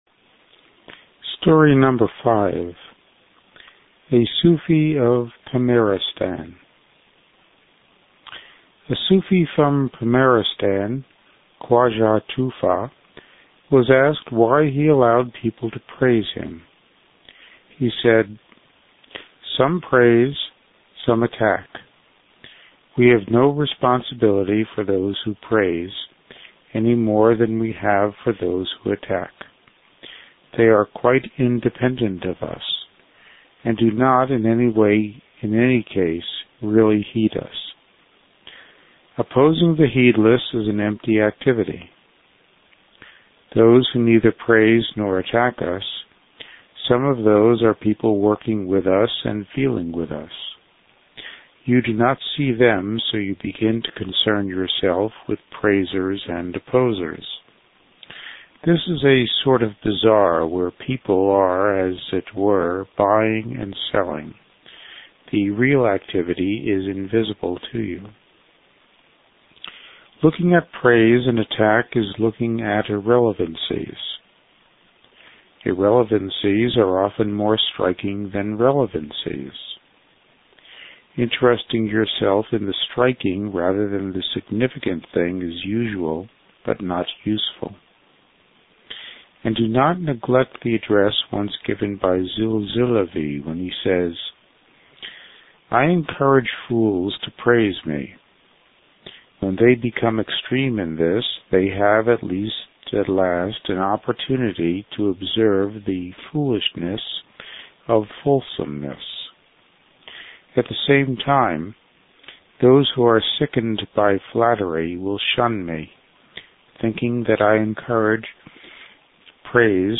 Note: each of the following begins with a reading of the story and then the commentary.